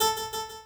harp16-01.ogg